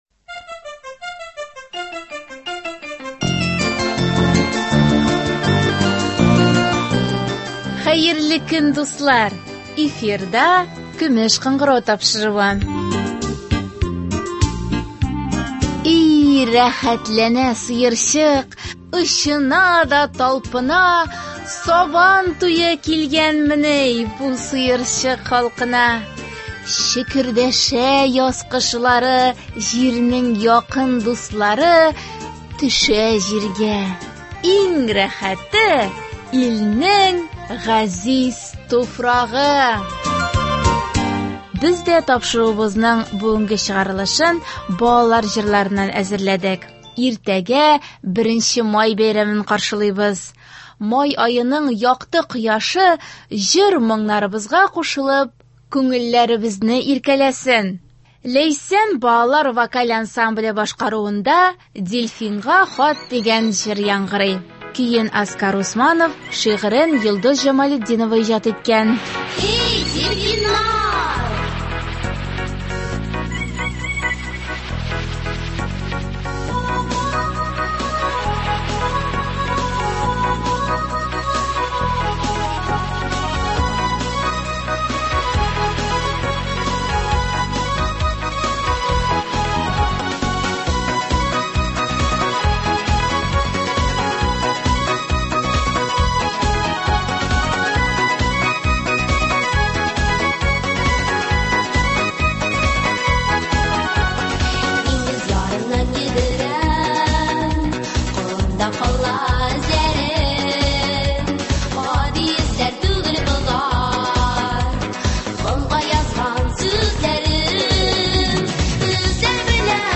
Тапшыруыбызның бүгенге чыгарылышын балалар җырларыннан әзерләдек.